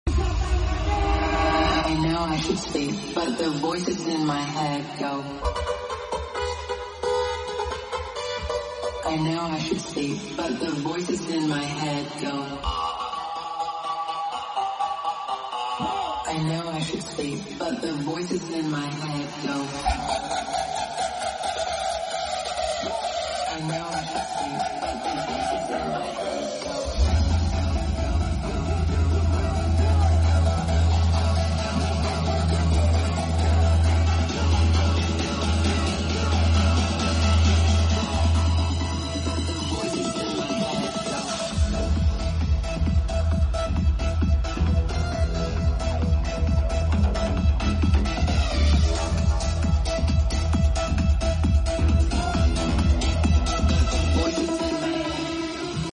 salah satunya main di festival di Tasikmalaya.
More Indobounce atau More Bigroom Techno?